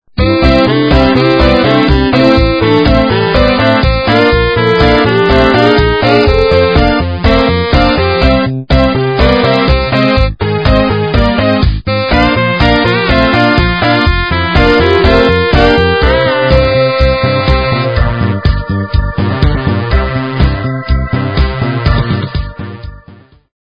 Polyphonic Ringtones